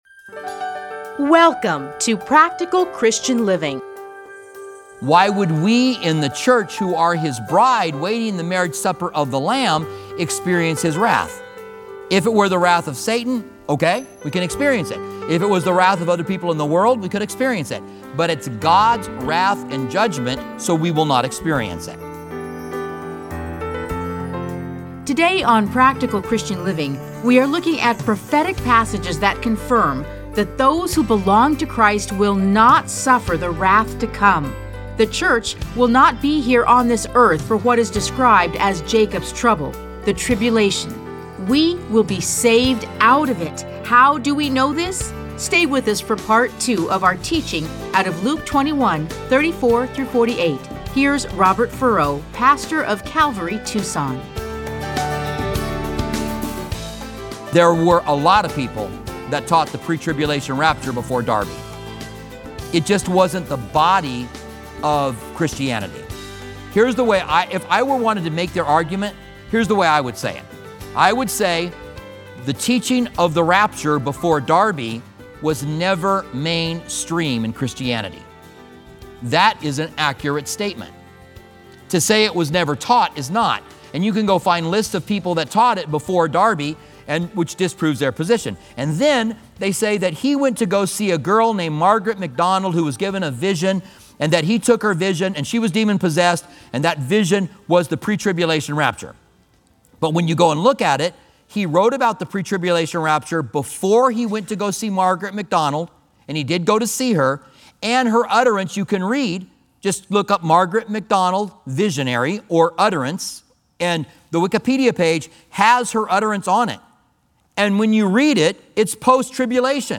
Listen to a teaching from Luke 21:34-38.